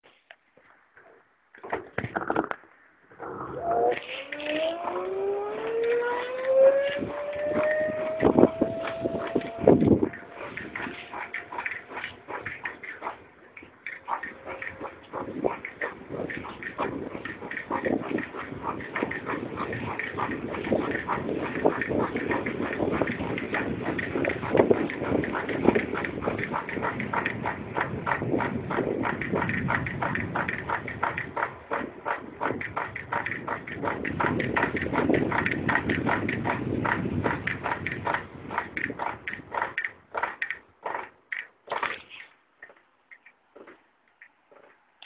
Ciao a tutti, da circa un mesetto sto sperimenando un rumore molto fastidioso proveniente dalla ruota posteriore della mia Euro 7.
Il rumore e' costante durante la marcia della bici ed e' prodotto con la stessa frequenza della pedalata.
Ho registrato il rumore con il mio cellulare e trovate la registrazione di seguito.
E' prodotto con la stessa frequenza di rotazione della ruota.